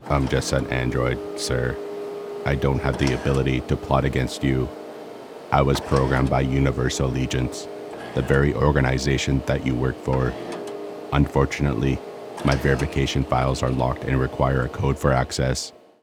Require-a-Code-for-Access-Character-Robotic_01.mp3